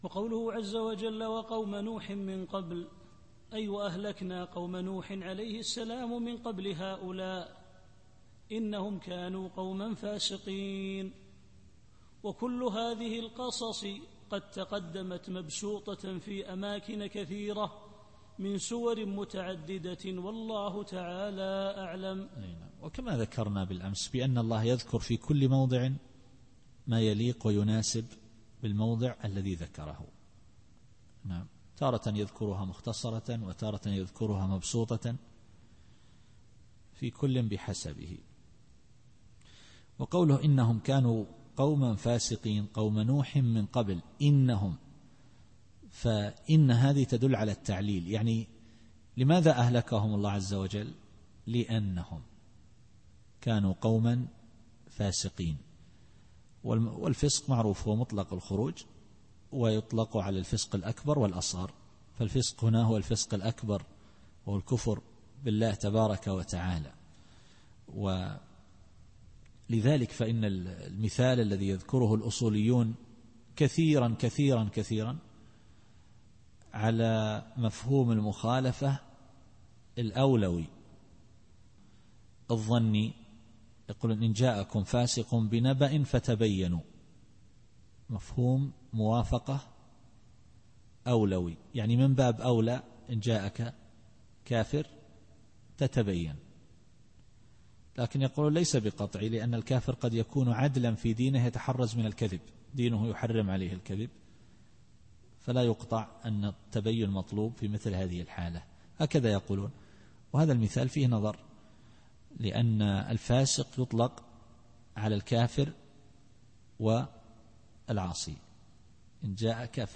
التفسير الصوتي [الذاريات / 46]